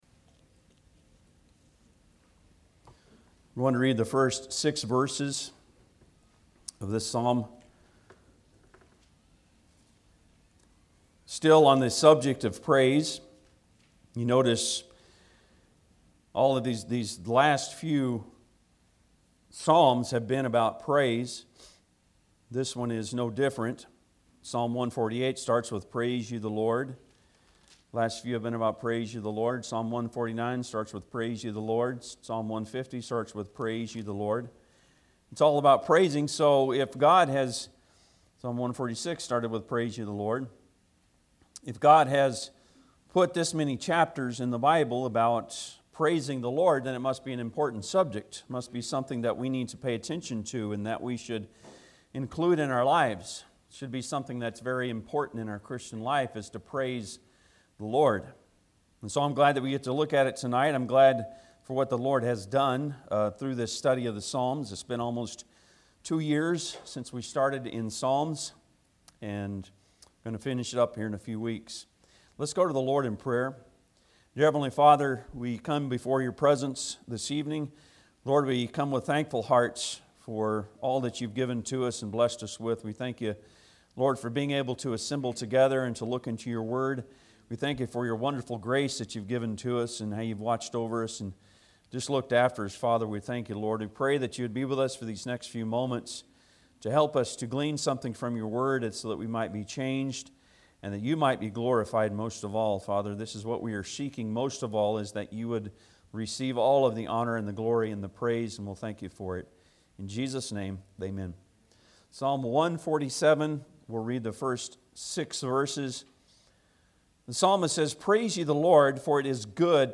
Psalms 147:1-6 Service Type: Sunday pm Bible Text